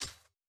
Impact Grenade.wav